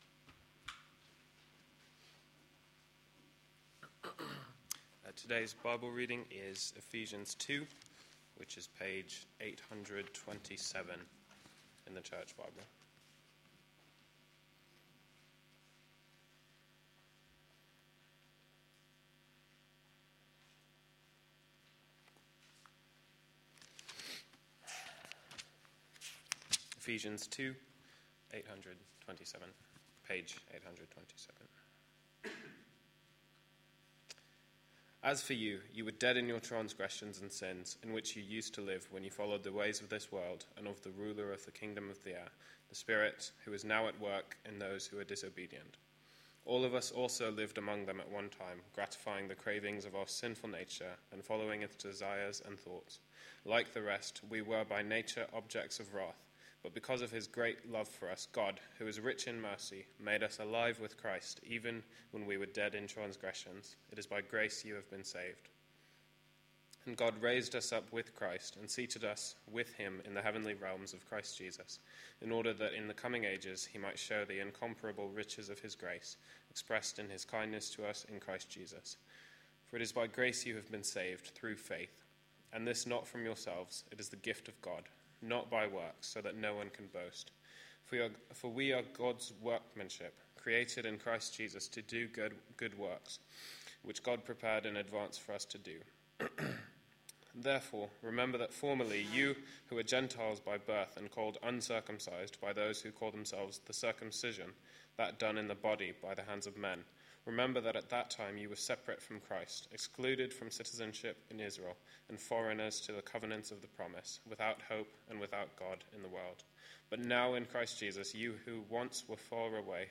A sermon preached on 20th May, 2012, as part of our Joining In series.